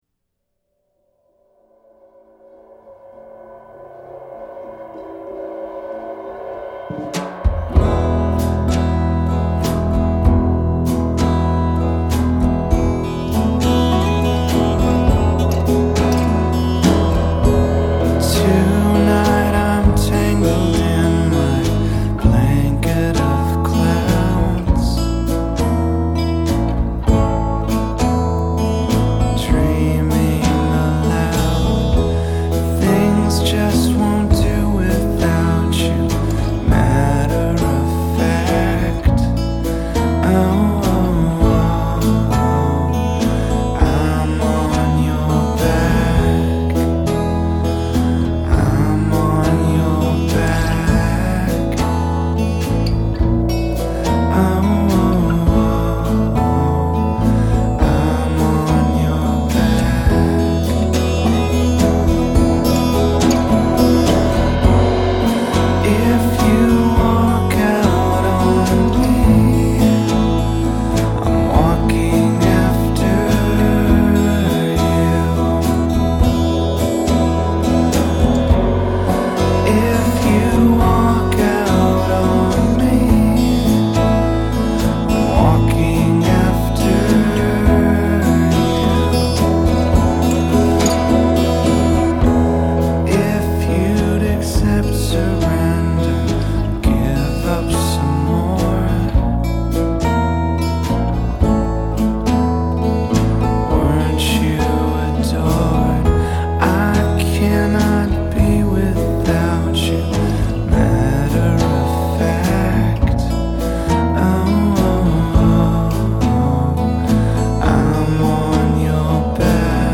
3. It’s like a grunge lullaby.
Tags1990s 1997 alternative grunge Rock Western US